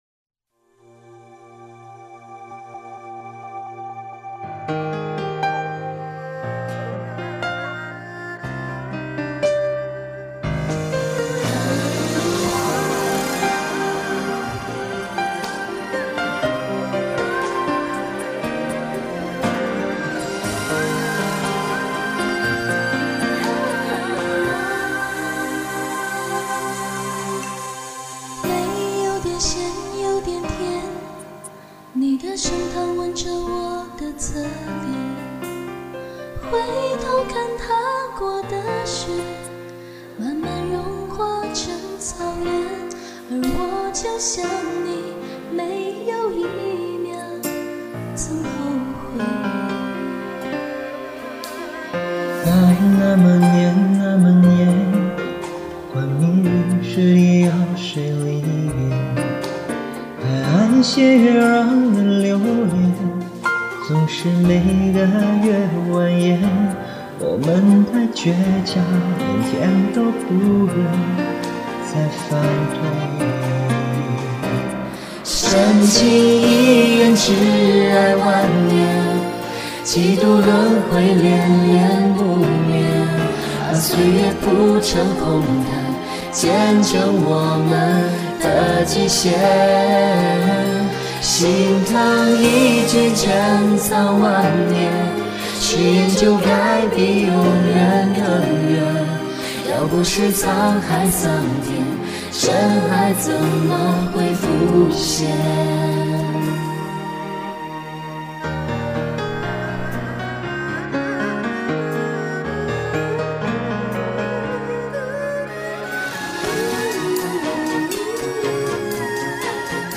BTW：女声的声音好甜~我羡慕这样的声线~~~:cn09: 我这辈子不可能了:cn03: